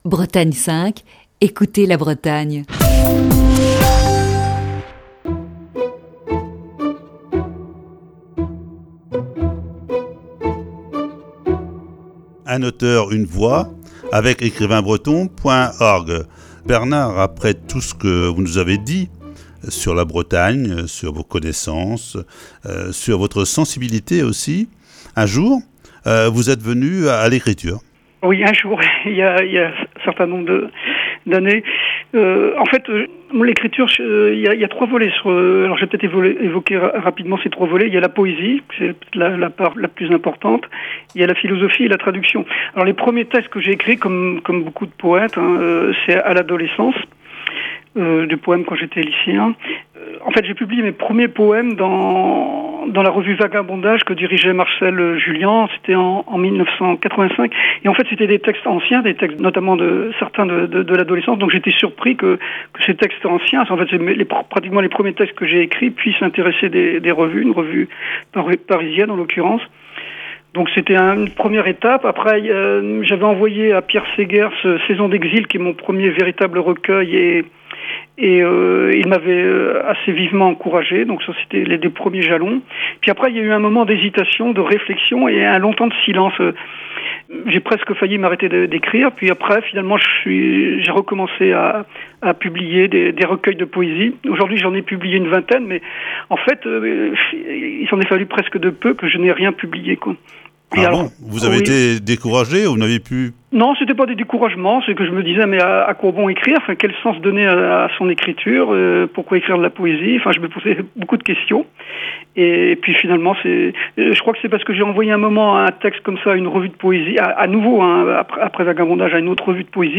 Voici ce mercredi la troisième partie de cette série d'entretiens.